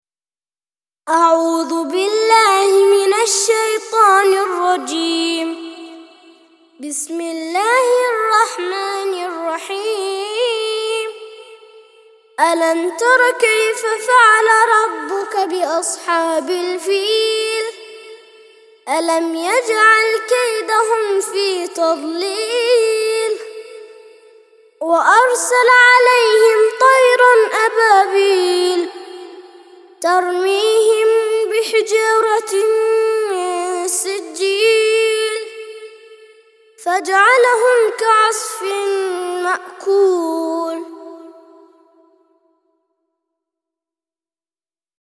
105- سورة الفيل - ترتيل سورة الفيل للأطفال لحفظ الملف في مجلد خاص اضغط بالزر الأيمن هنا ثم اختر (حفظ الهدف باسم - Save Target As) واختر المكان المناسب